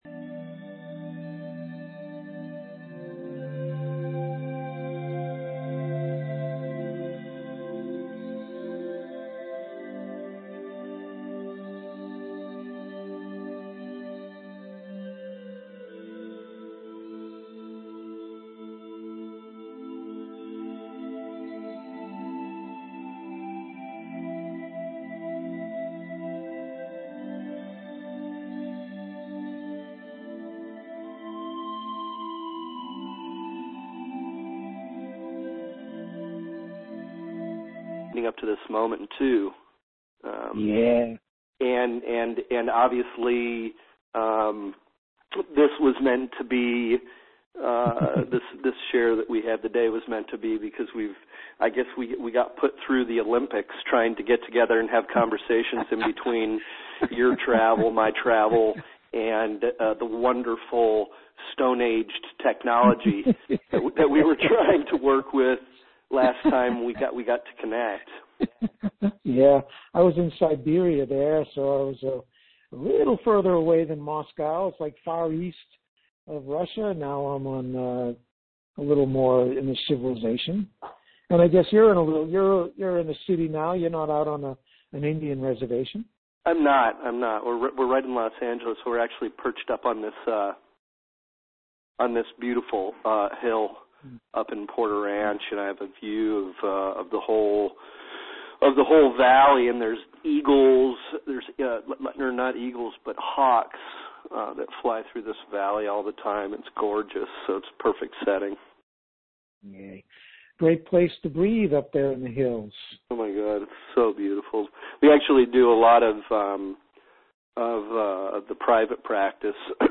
This valuable teleseminar is only one example of many Information Products available for FREE to all Breath Mastery Inner Circle Members.